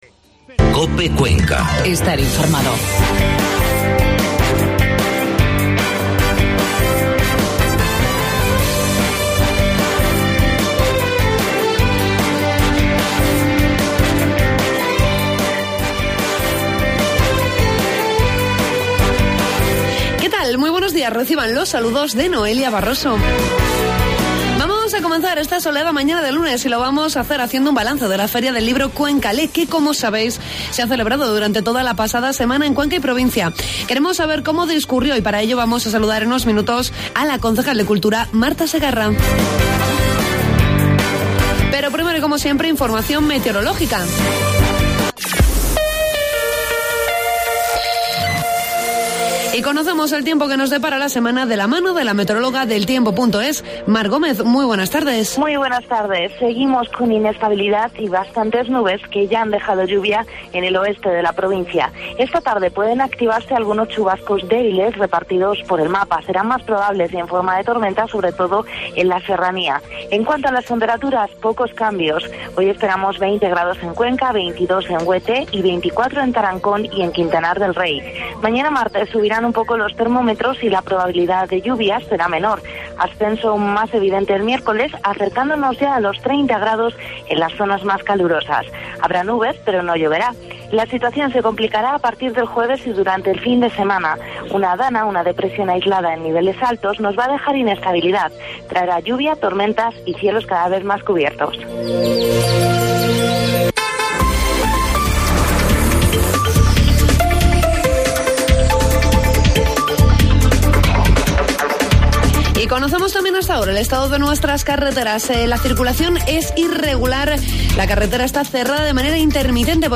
Hacemos balance de la feria del libro 'Cuenca Lee' con la concejal de Cultura, Marta Segarra.